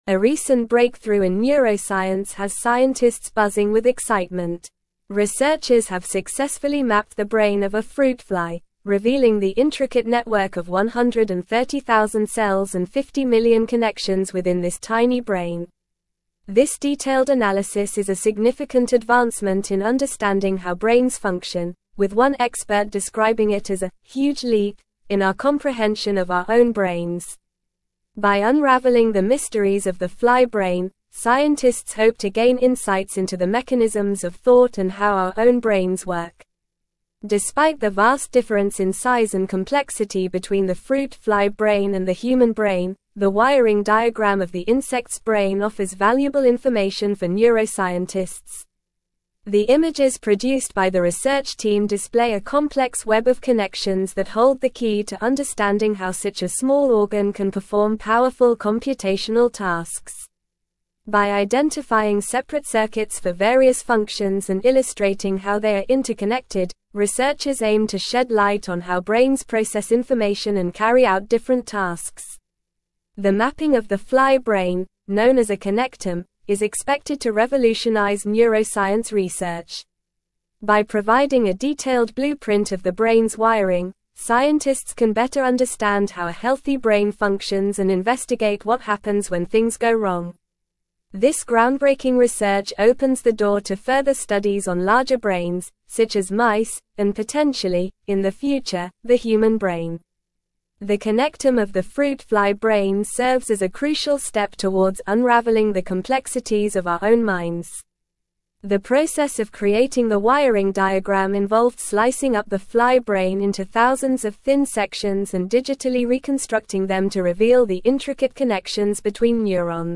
Normal
English-Newsroom-Advanced-NORMAL-Reading-Groundbreaking-Neuroscience-Discovery-Mapping-a-Flys-Brain-Connections.mp3